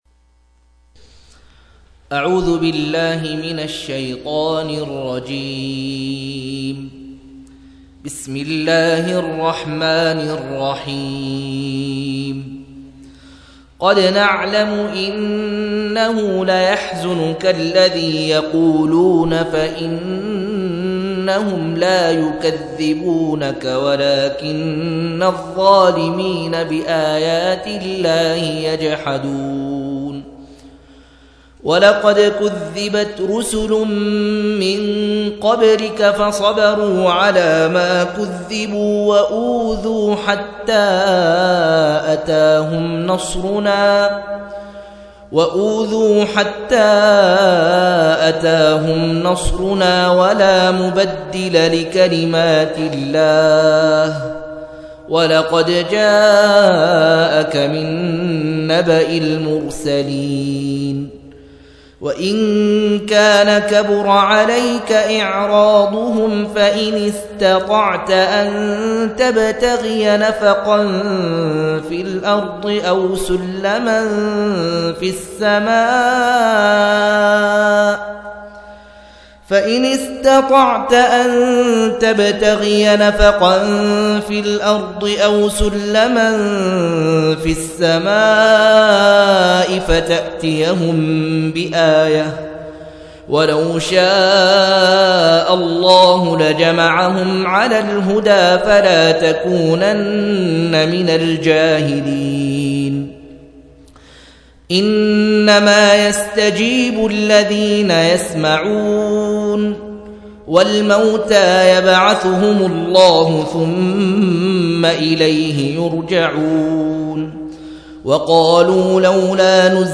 128- عمدة التفسير عن الحافظ ابن كثير رحمه الله للعلامة أحمد شاكر رحمه الله – قراءة وتعليق –